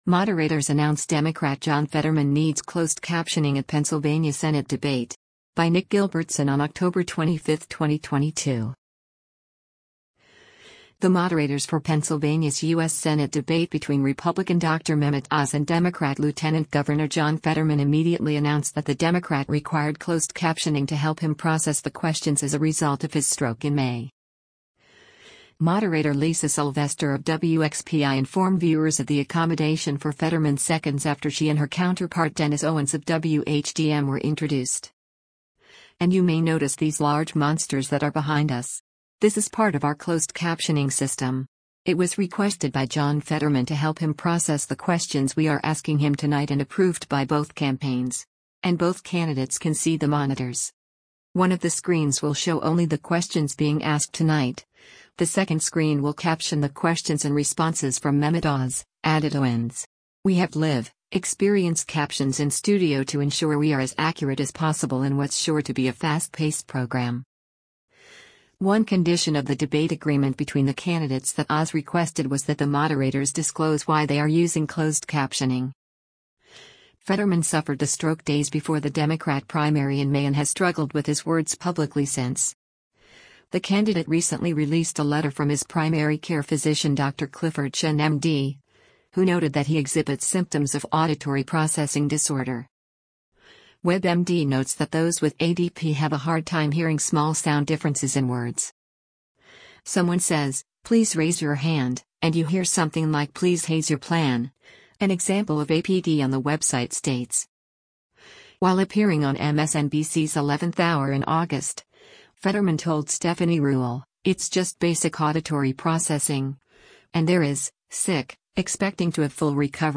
Moderators Announce Democrat John Fetterman Needs Closed Captioning at Pennsylvania Senate Debate